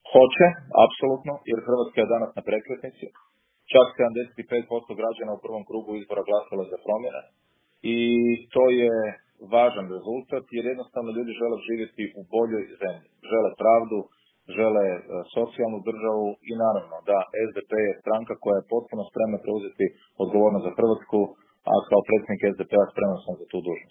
Davor Bernardić u intervjuu Media servisa o aktualnoj predsjedničkoj kampanji